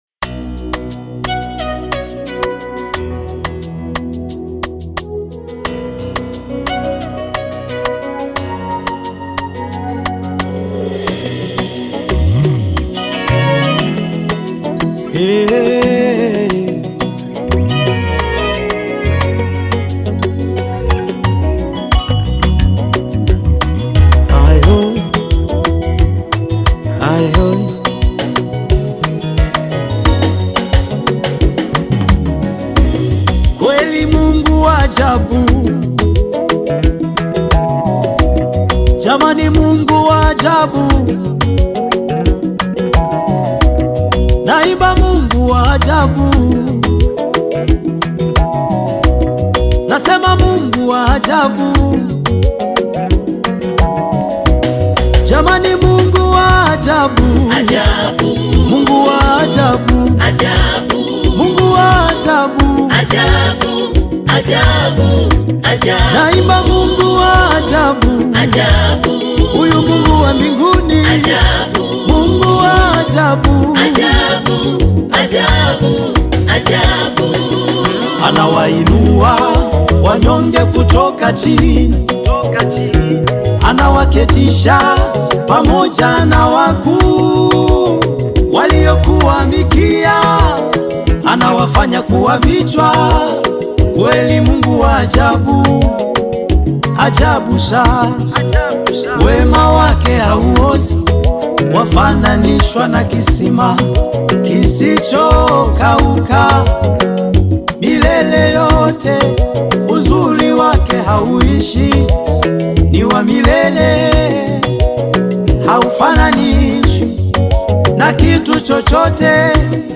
Tanzanian gospel singer and songwriter
gospel song
Gospel song